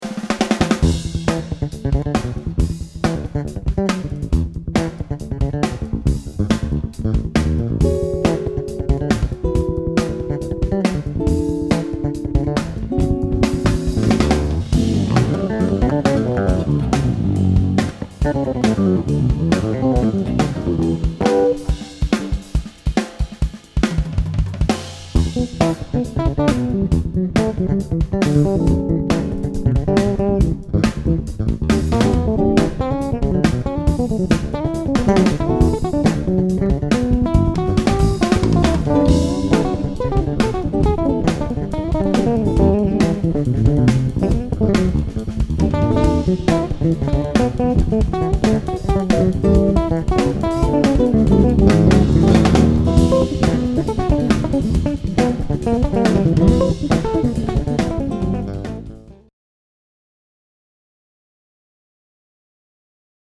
electric bass